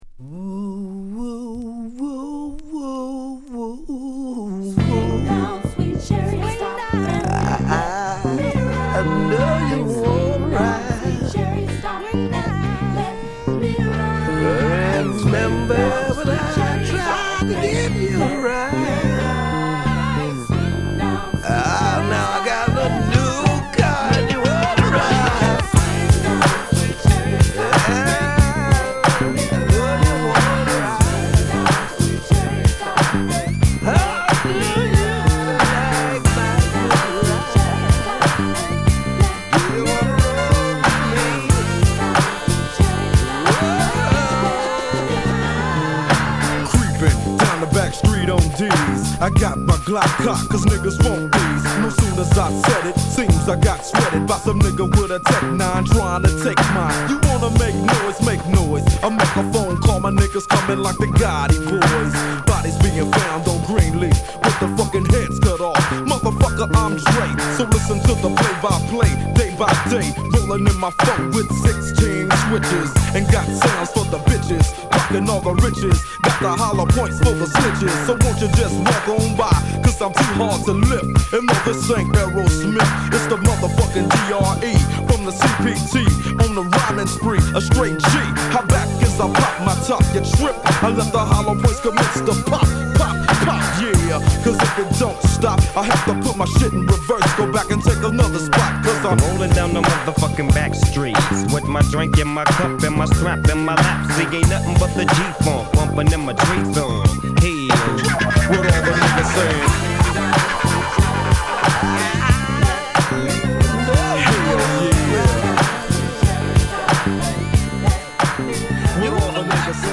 悲しげなエレピに、揺れるビート！